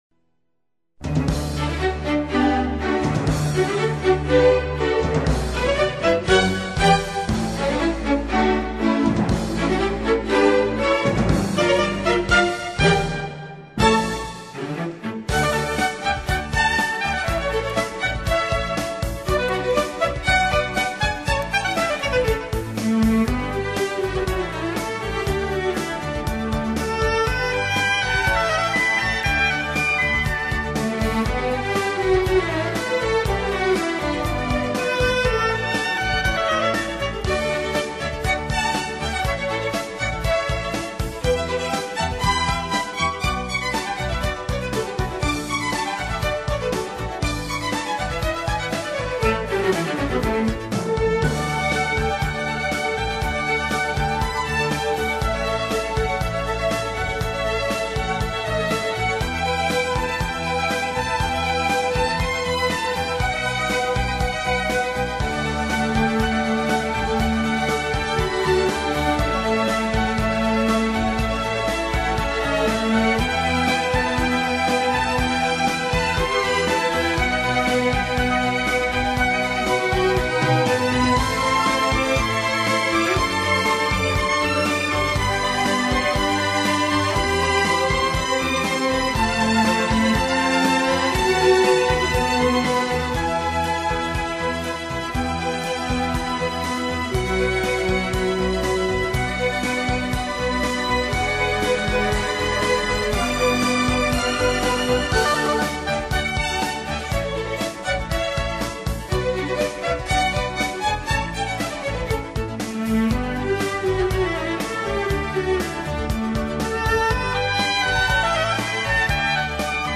[스크랩] Musica Fantasia 外 6곡 - 밝고 아름다운 선율의 연주곡
밝고 아름다운 클래식 선율 1.